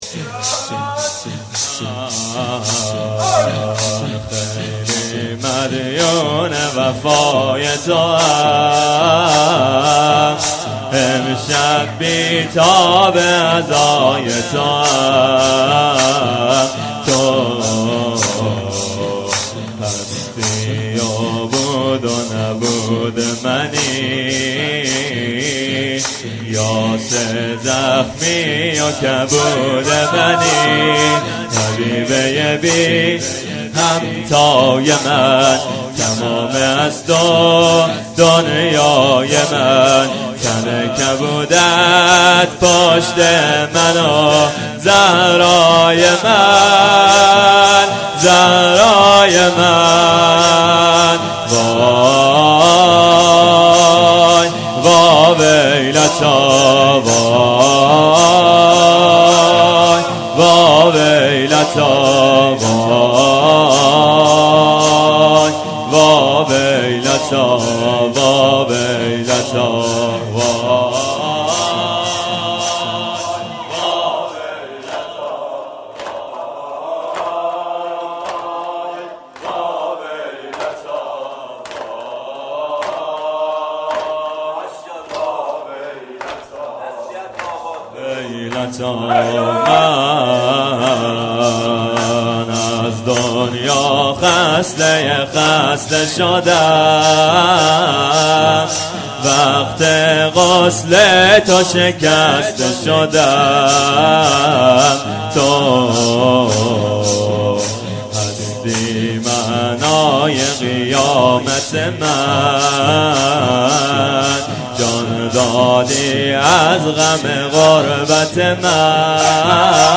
مراسم شب ششم فاطمیه دوم 93/94